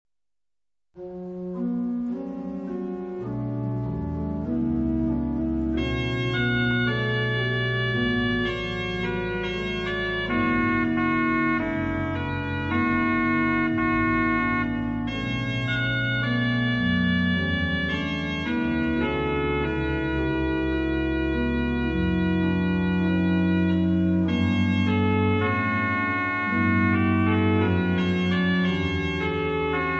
Per organo